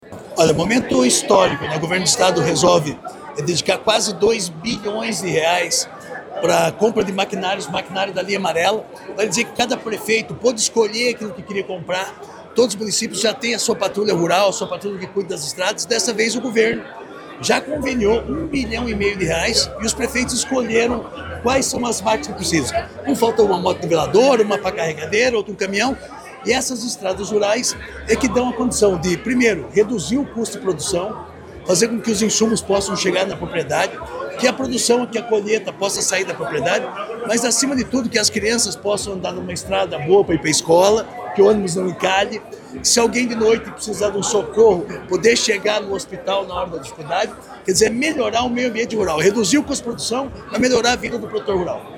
Sonora do secretário Estadual da Agricultura e Abastecimento, Marcio Nunes, sobre a liberação de R$ 100 milhões para máquinas para estradas rurais na RMC